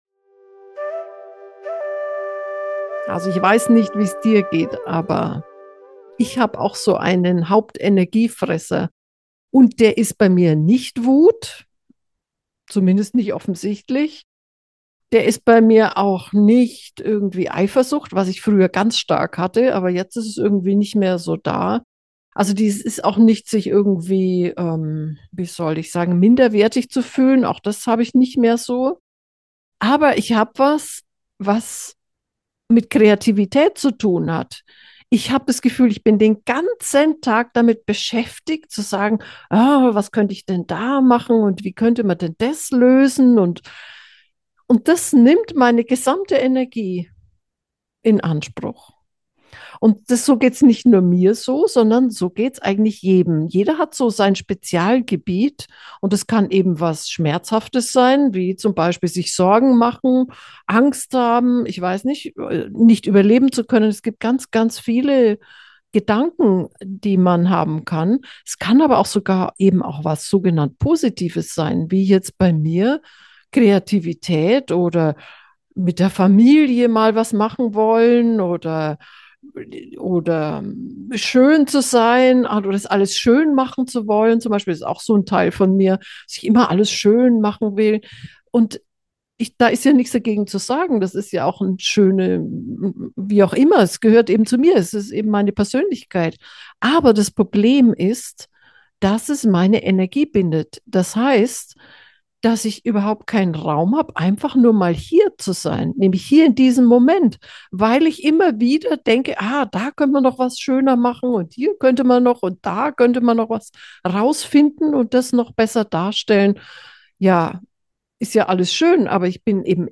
energie-raeuber-fresser-meditation